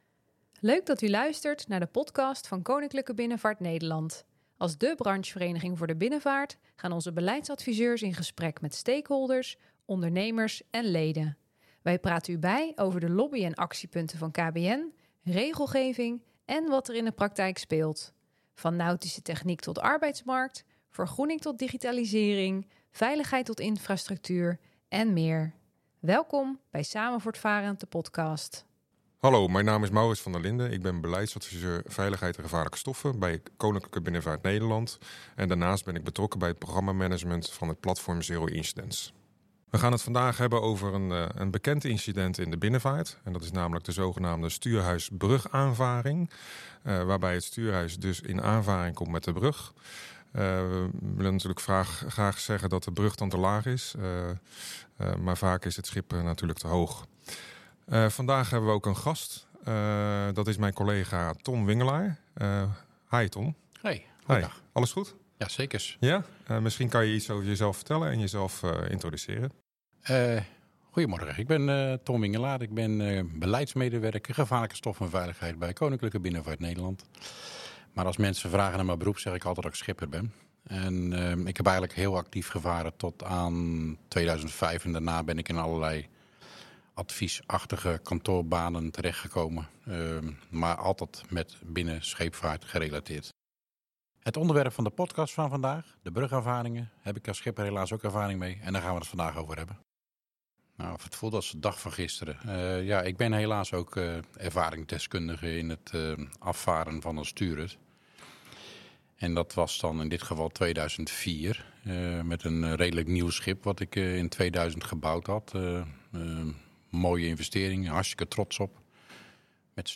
met elkaar in gesprek over een helaas nog veel voorkomend ongeval: de stuurhuis- brugaanvaring.